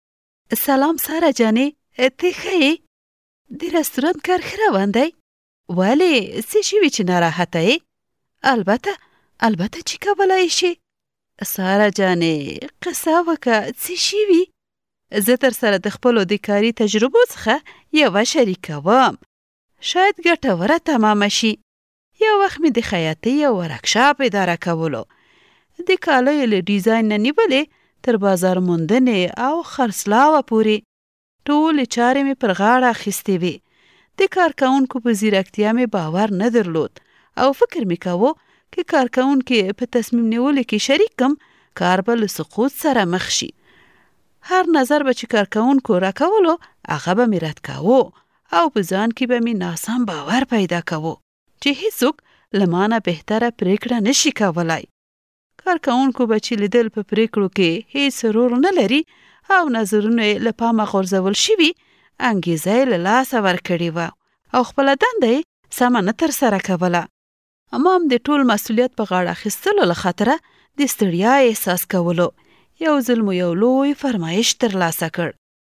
Professional Female Pashto Voice Samples
Our female Pashto voice artists offer a wide range of tones.
FEMALE_PASHTO-1.mp3